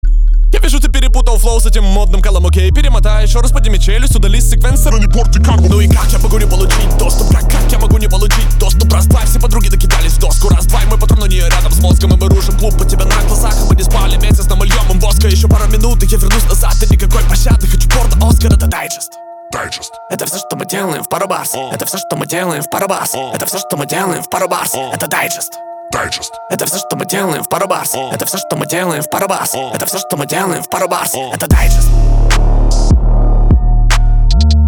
• Качество: 320, Stereo
Хип-хоп
Trap
качающие
Bass
речитатив